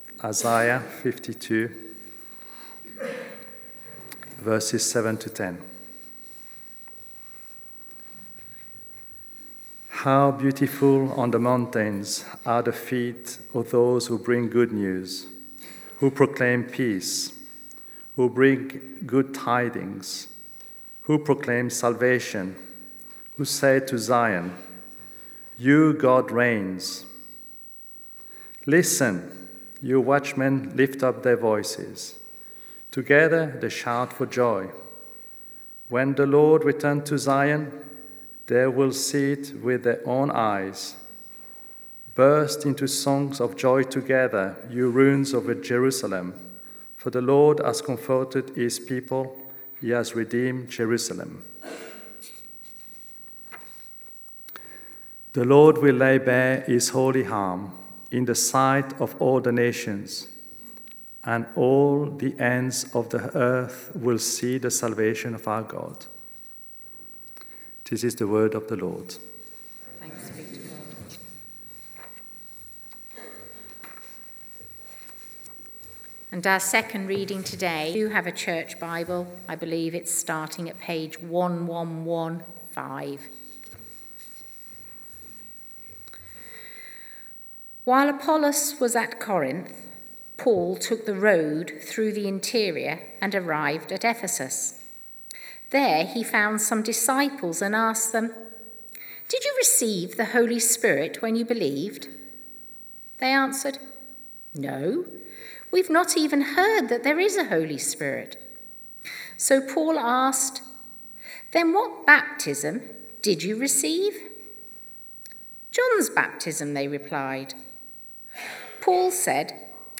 Emmanuel Church Sermons Ephesus- A Church that Multiplies Play Episode Pause Episode Mute/Unmute Episode Rewind 10 Seconds 1x Fast Forward 30 seconds 00:00 / 30:30 Subscribe Share RSS Feed Share Link Embed